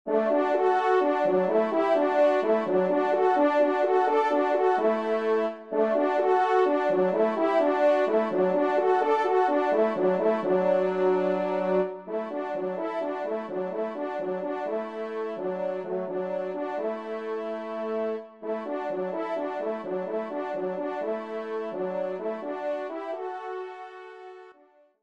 Arrangement Trompe et Piano
2e Trompe